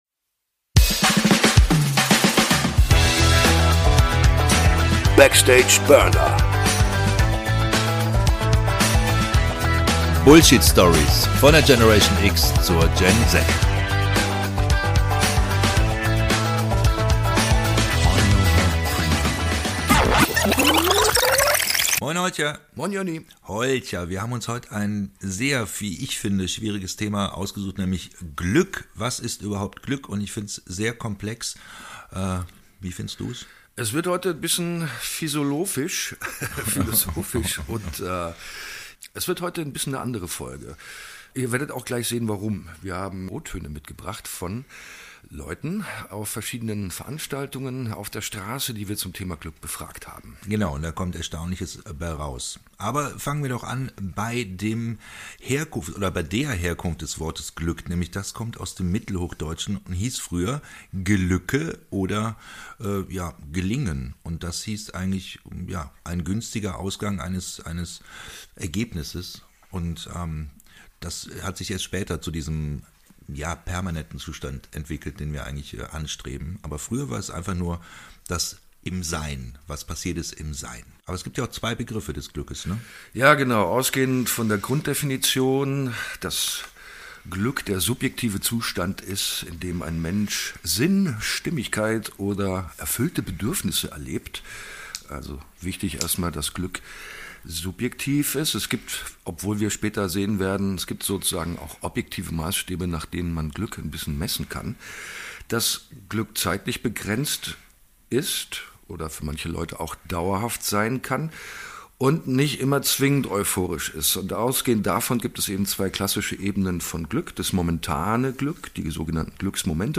Und wir haben uns Gedanken gemacht und auf der Strasse nachgefragt.